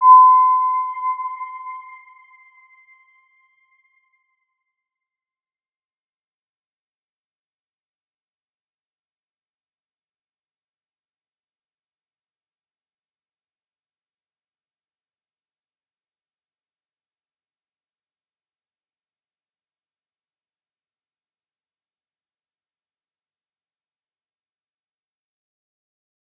Round-Bell-C6-p.wav